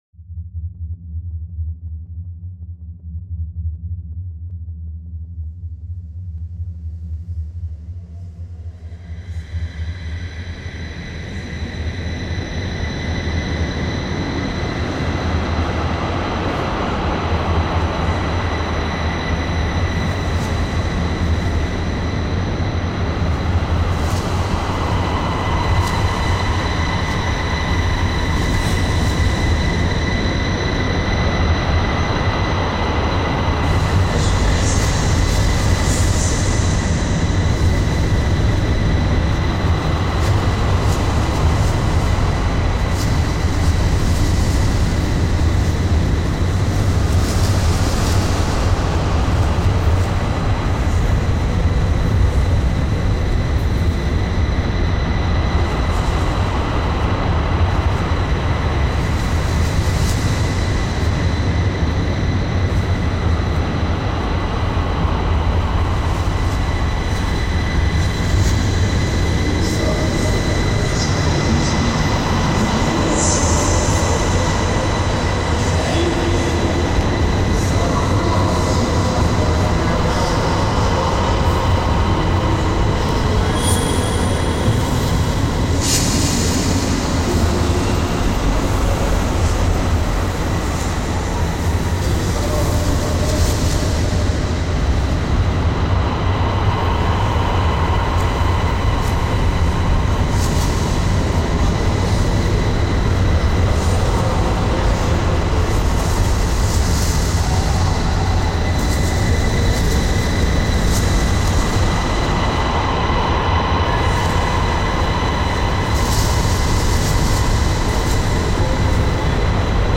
Here’s a track I made from recordings taken on the trip.